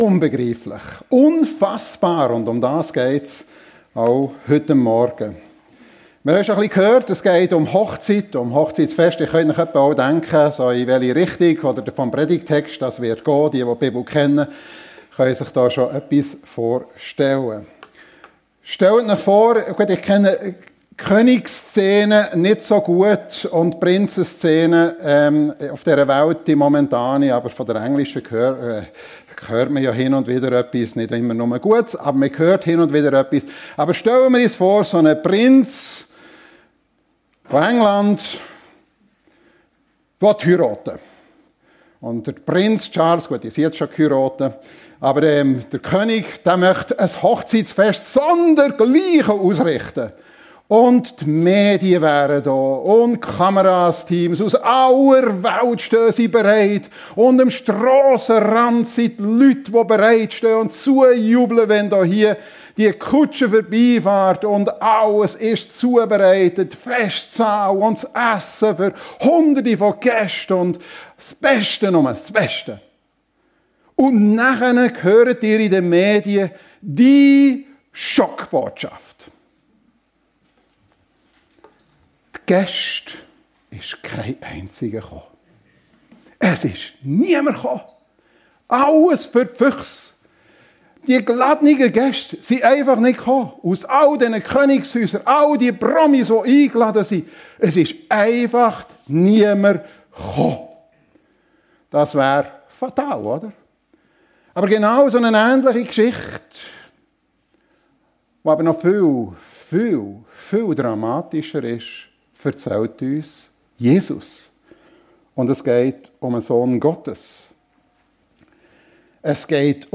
Herzlich willkommen zum königlichen Hochzeitsfest ~ FEG Sumiswald - Predigten Podcast